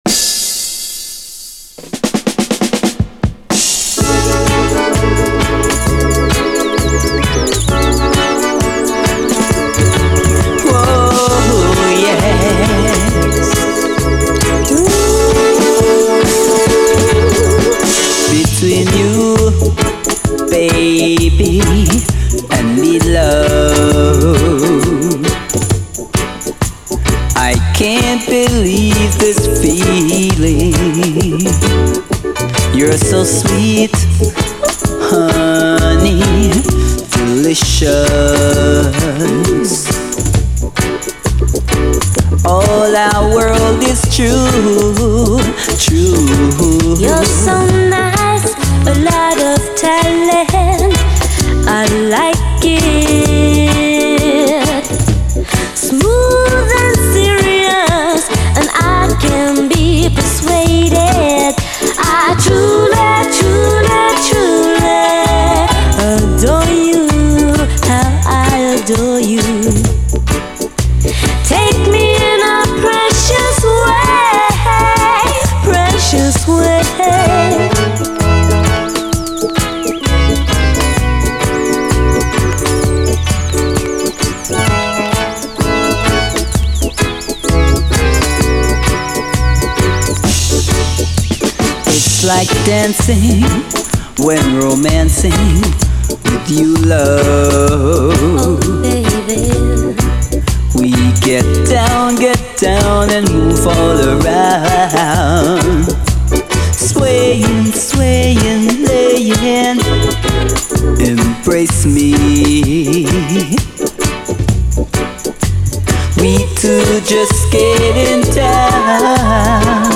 REGGAE
超最高UKラヴァーズ！
鳥の鳴き声風SEも入り
後半はダブに接続。